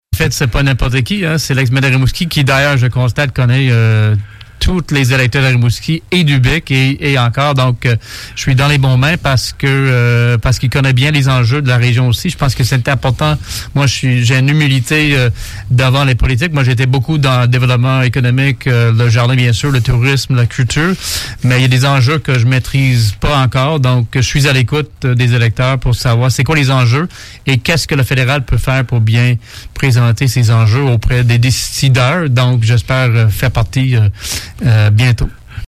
Invité à la populaire émission du doyen des animateurs de radio